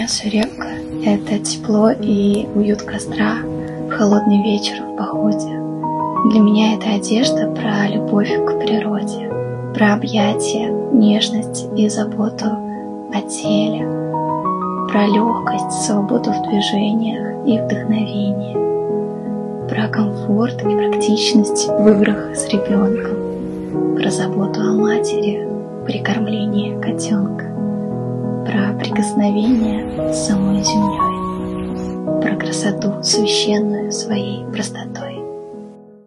Жен, Рекламный ролик/Молодой
Мои демо были записаны на самые разные устройства, чтобы вы могли ознакомиться со звучанием моего голоса.